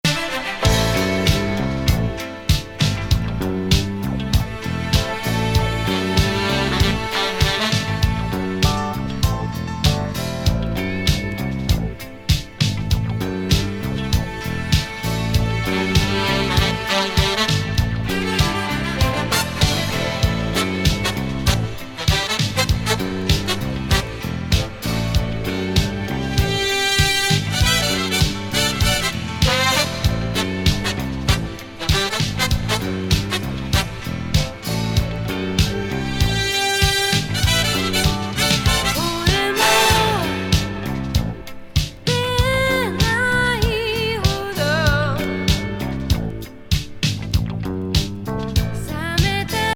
NYミックスのソウルフルな82年作。